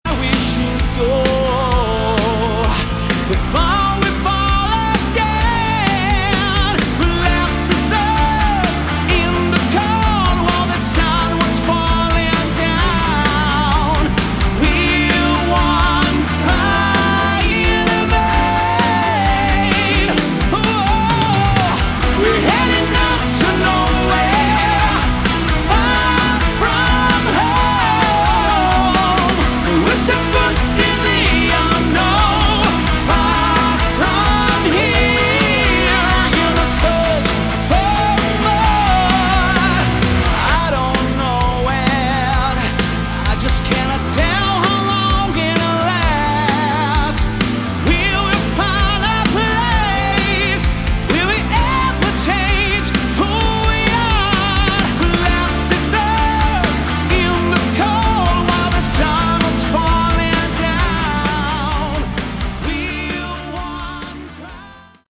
Category: Melodic Hard Rock
Guitars
Drums
Bass
Backing vocals